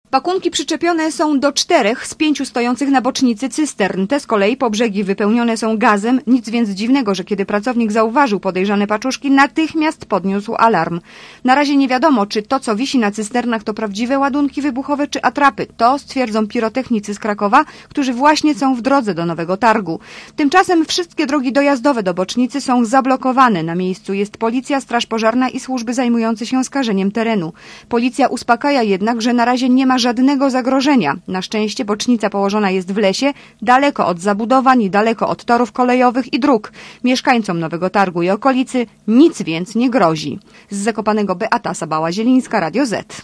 nowy_targ_-_cysterny.mp3